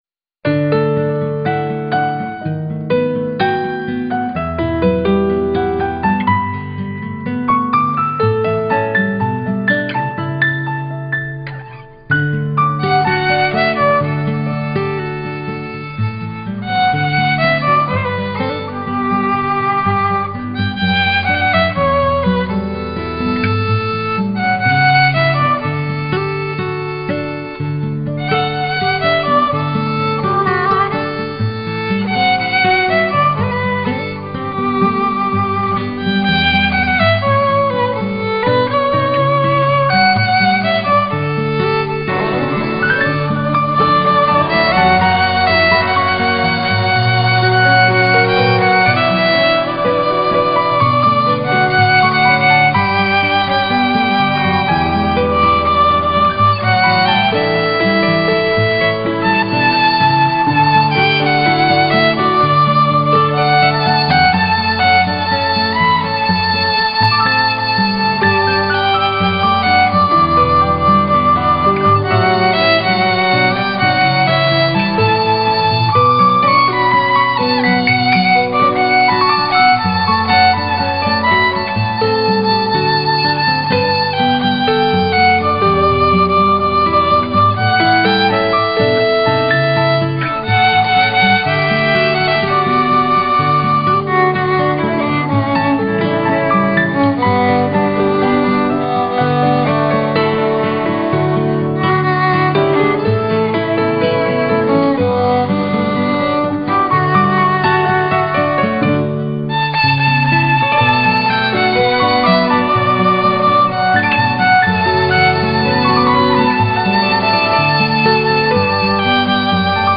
Concierto en acústico
vallenato
acústico
instrumental